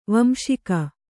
♪ vamśika